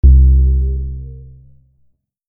Reagge Dreadblock Bass Oscar C1 dread_bass
dread_bass.mp3